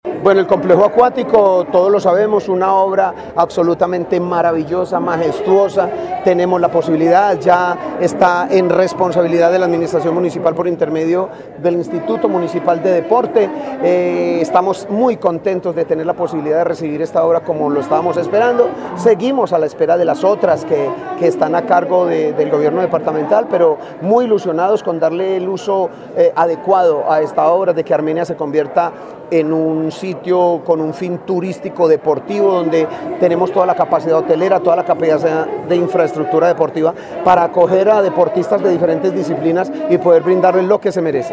Alcalde de Armenia, James Padilla